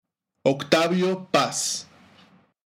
^ Spanish pronunciation: [oɣˈtaβjo pas loˈsano],